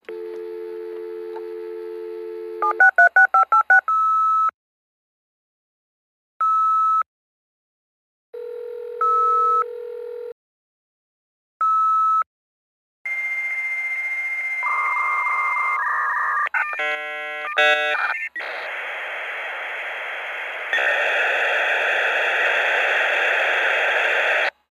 Computer modem dialing Internet. Modem, Computer Beep, Modem